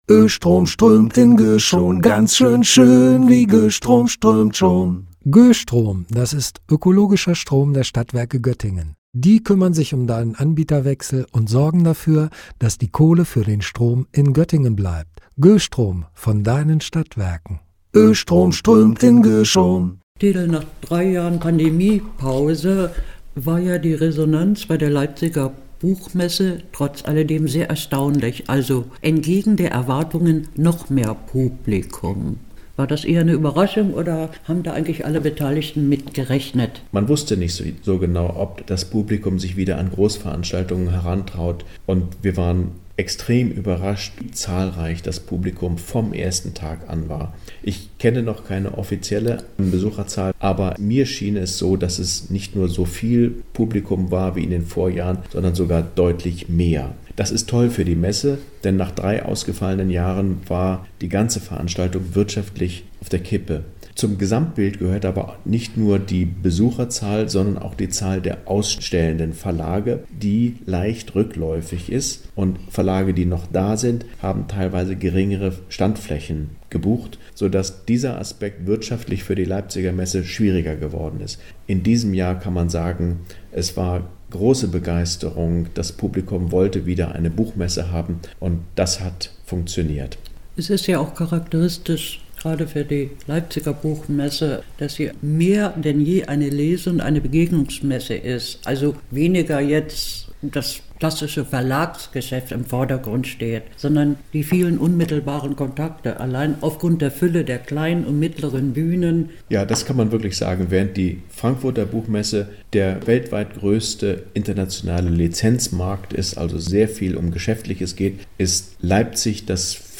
Gespräch